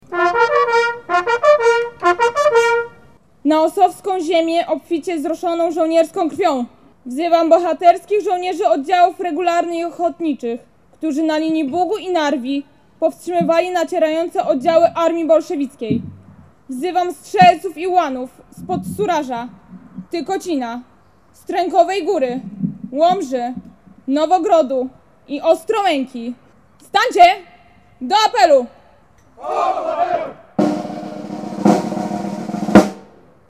Po uroczystej Mszy Świętej w Ossowie pod przewodnictwem abp. Stanisława Gądeckiego odczytano apel poległych i złożono wieńce na Cmentarzu Bohaterów 1920.